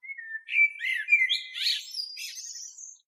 На этой странице собраны натуральные звуки чириканья птиц в высоком качестве.
Настоящий звук птичьего чириканья